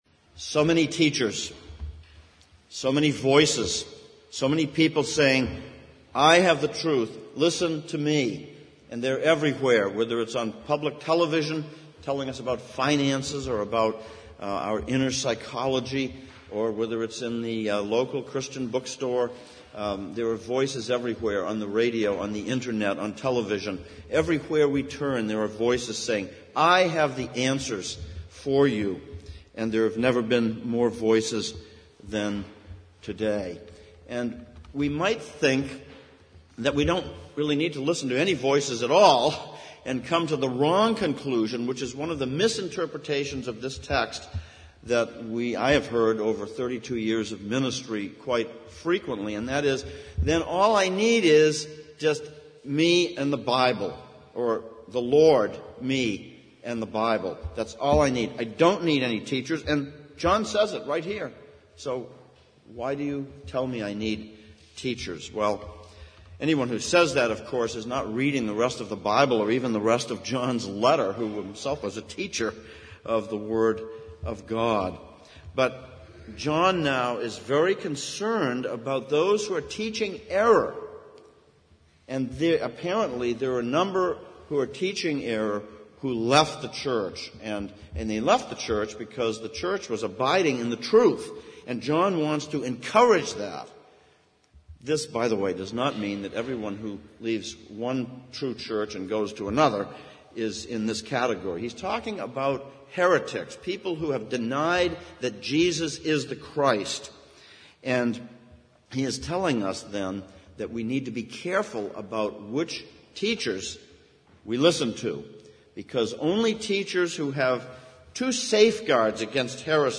Service Type: Sunday Morning Sermon